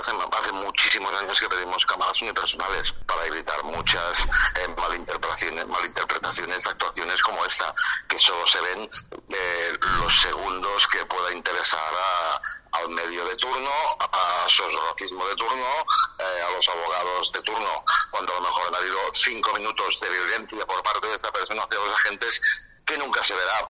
Esta es la versión del sindicato de Mossos USPAC a COPE